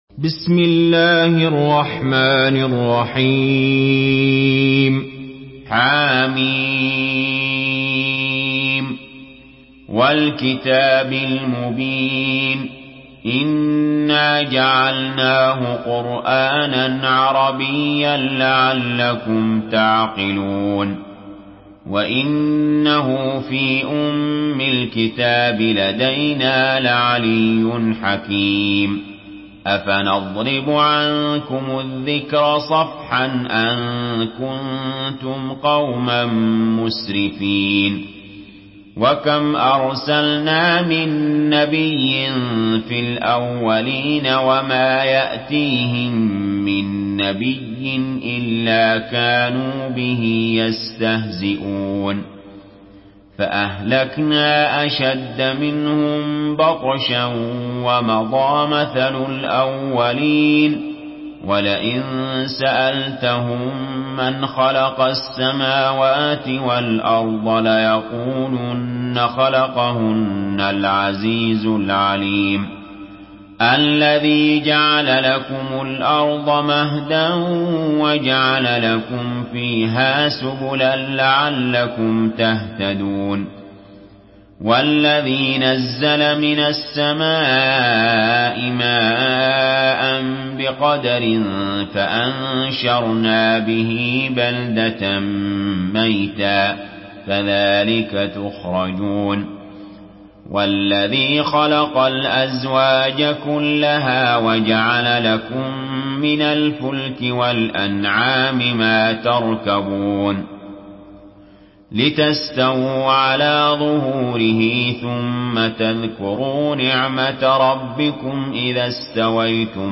Surah Az-Zukhruf MP3 in the Voice of Ali Jaber in Hafs Narration
Murattal